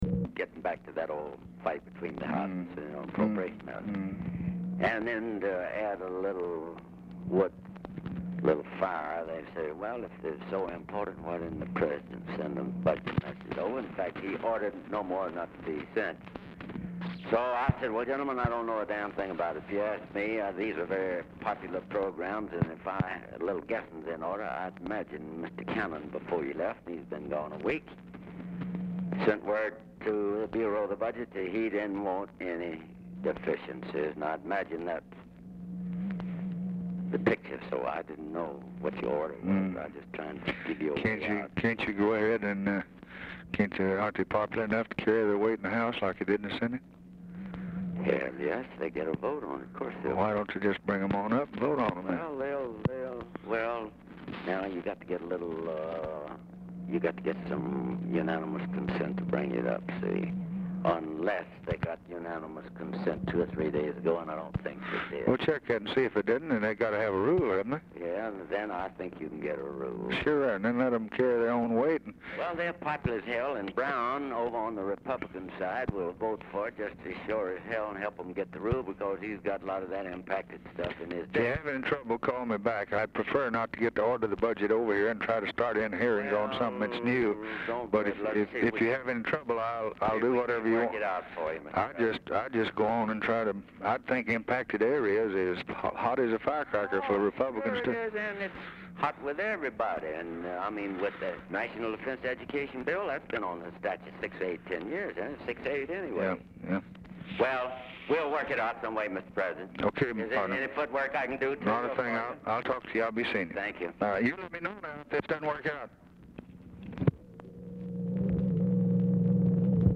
Telephone conversation # 587, sound recording, LBJ and ALBERT THOMAS
RECORDING STARTS AFTER CONVERSATION HAS BEGUN
Format Dictation belt
Oval Office or unknown location